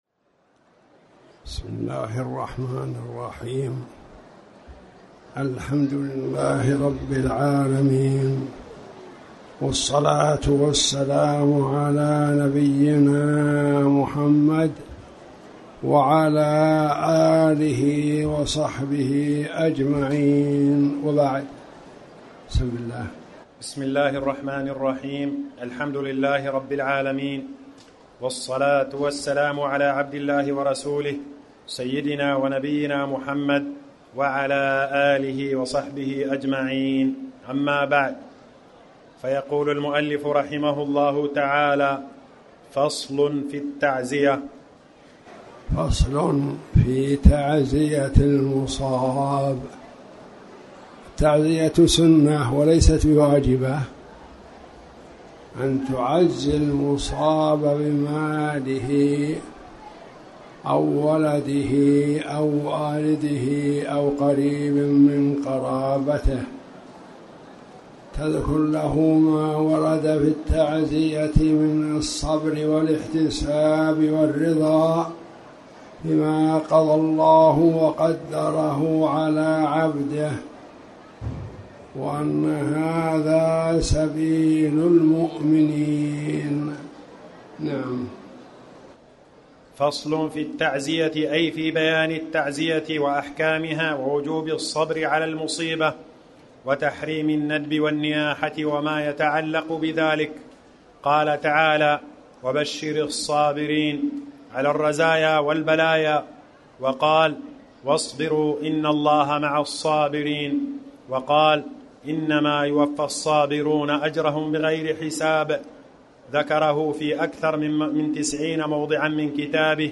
تاريخ النشر ١٩ شعبان ١٤٣٩ هـ المكان: المسجد الحرام الشيخ